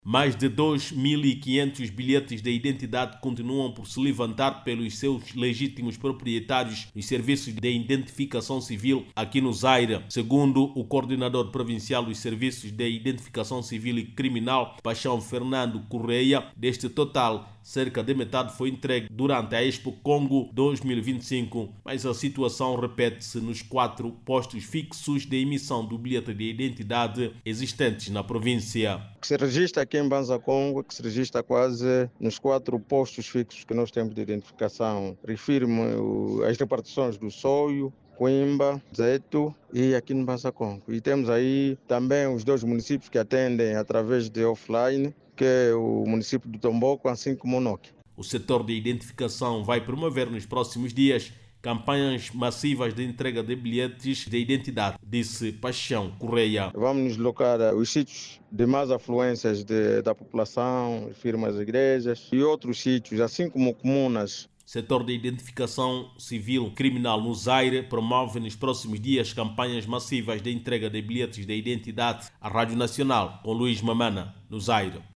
A situação preocupa as autoridades do sector que decidiram pela realização de campanhas massivas de entrega do documento. Clique no áudio abaixo e ouça a reportagem